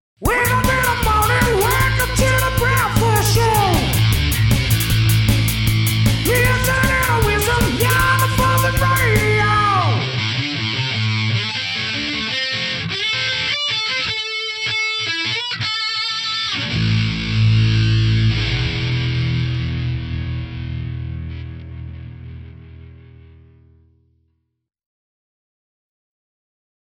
Rock Jingle